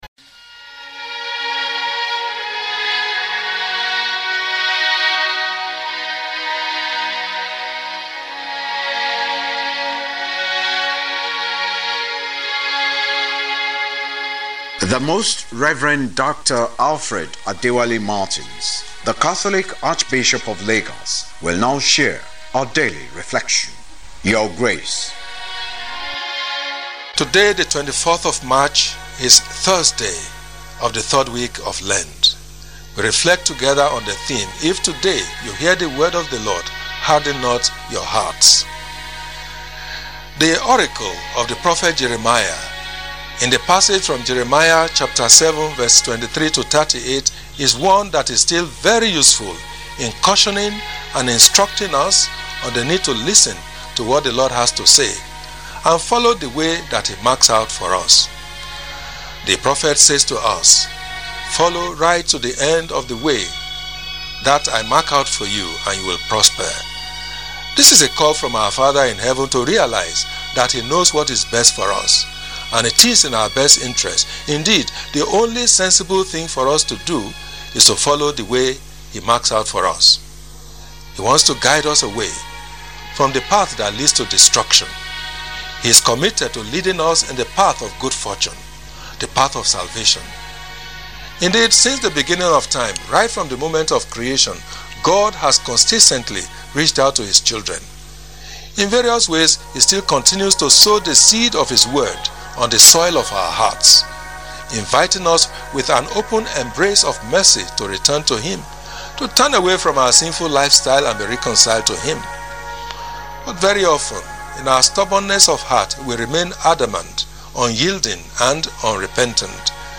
LENTEN REFLECTION WITH ARCHBISHOP MARTINS
LENTEN-TALK-THURSDAY.mp3